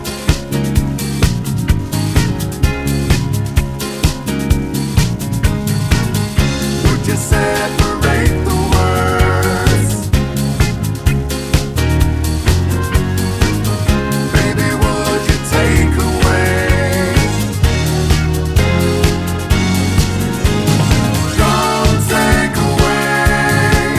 Two Semitones Down